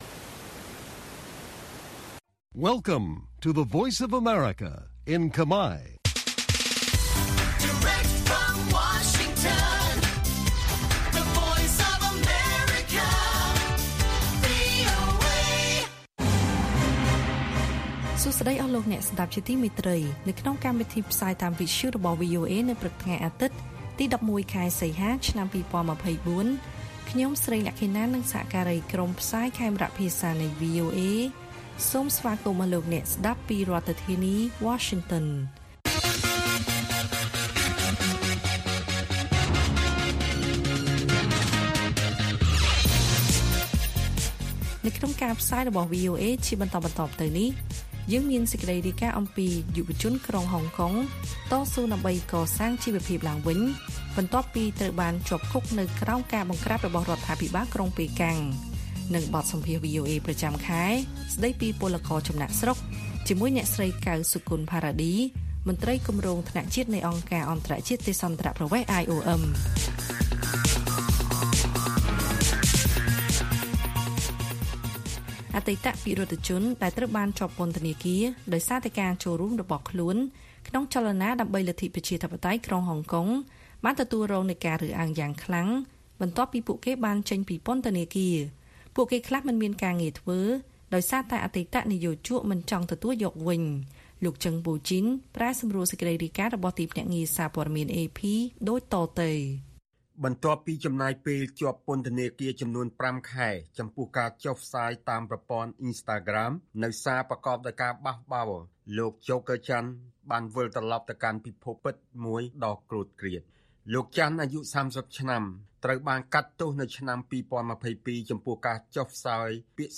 ព័ត៌មានពេលព្រឹក
Listen Live - កម្មវិធីវិទ្យុពេលព្រឹក - វីអូអេ - VOA Khmer